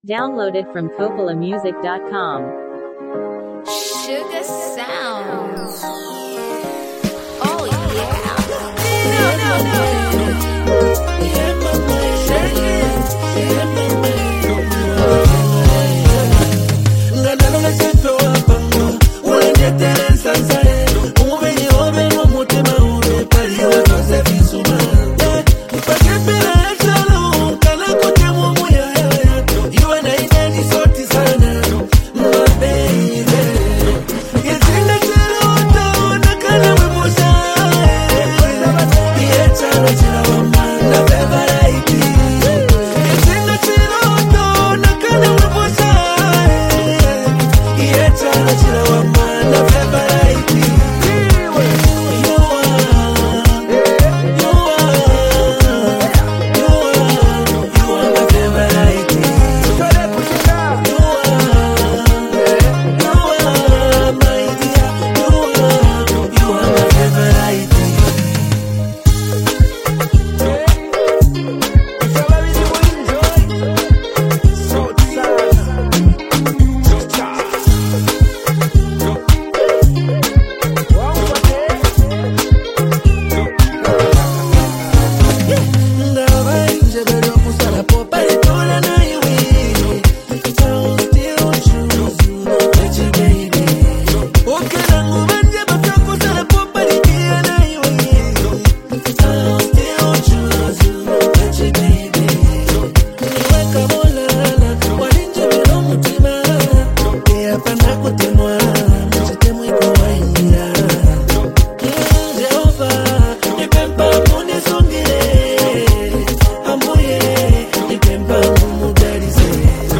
is a smooth and heartfelt love song
delivers warm vocals over a catchy, melodic beat